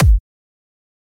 normal-hitwhistle.wav